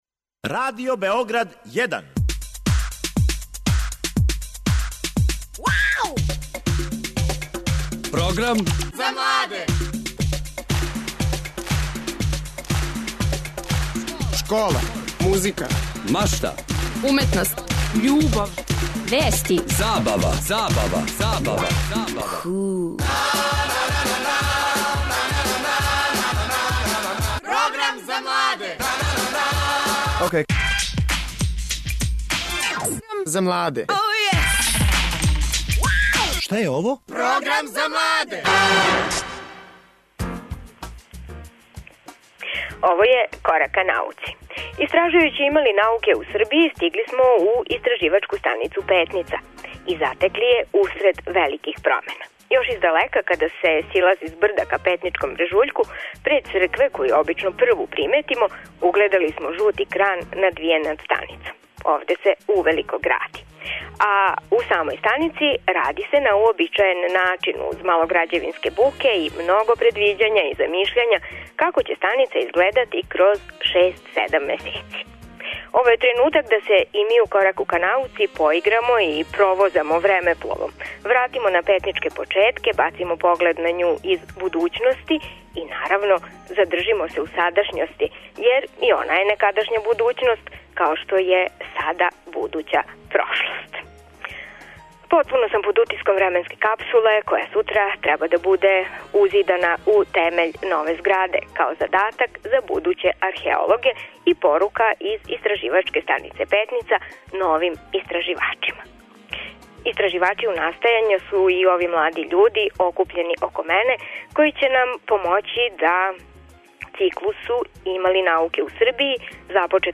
Настављамо циклус емисија у којима постављамо питање - има ли науке у Србији? Овог четвртка смо у Петници, где је у току међународни семинар.